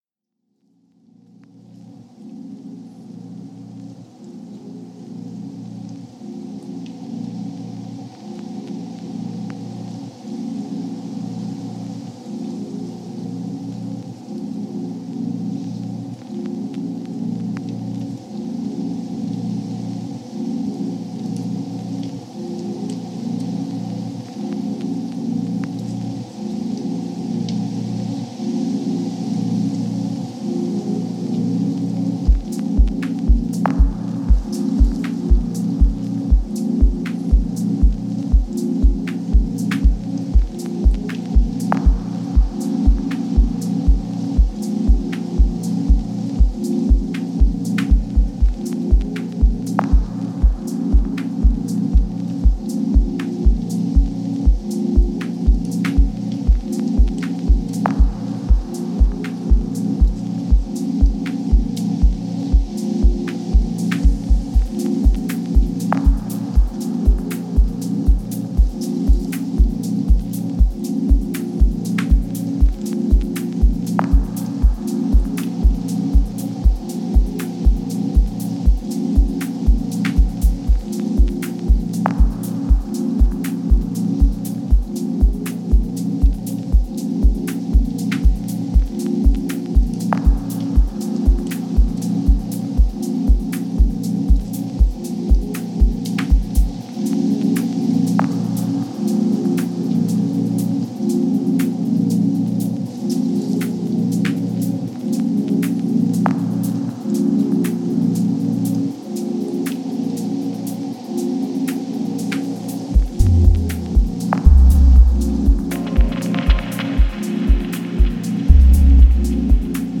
Genre: Ambient/Dub Techno.